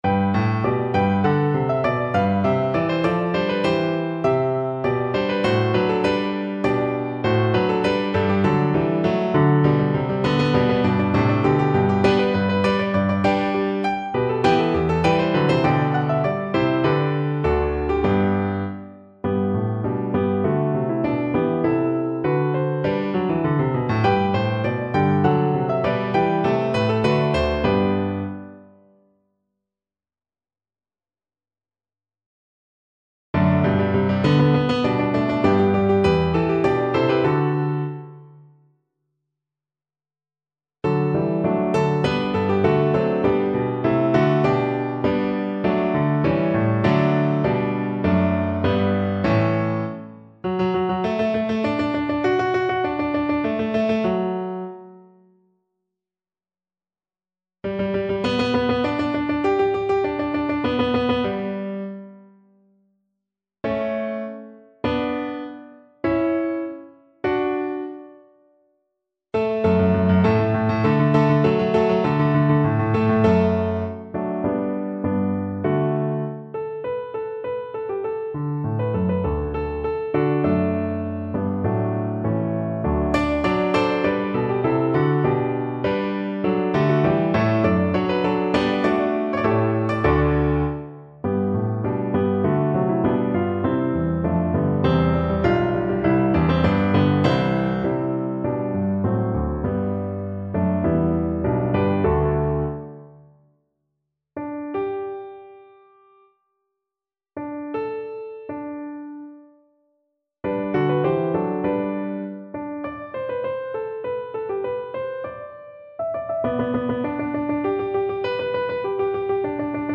Play (or use space bar on your keyboard) Pause Music Playalong - Piano Accompaniment Playalong Band Accompaniment not yet available transpose reset tempo print settings full screen
Cello
G major (Sounding Pitch) (View more G major Music for Cello )
Moderato
4/4 (View more 4/4 Music)
Classical (View more Classical Cello Music)